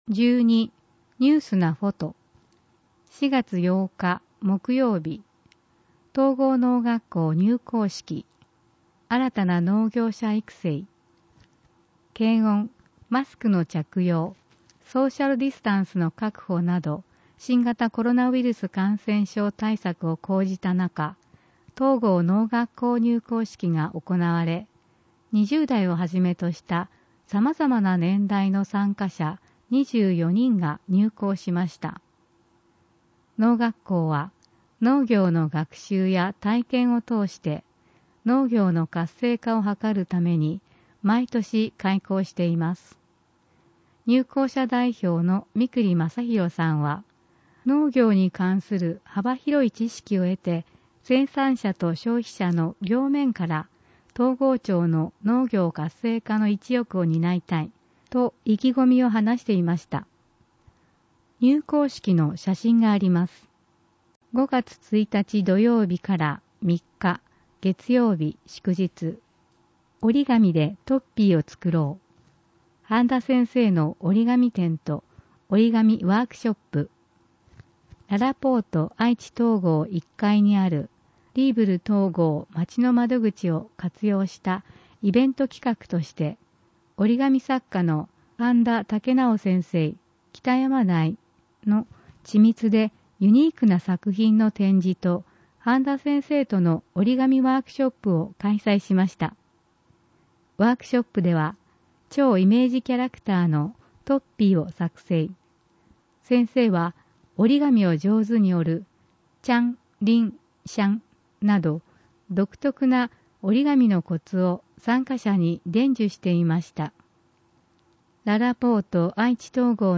広報とうごう音訳版（2021年6月号）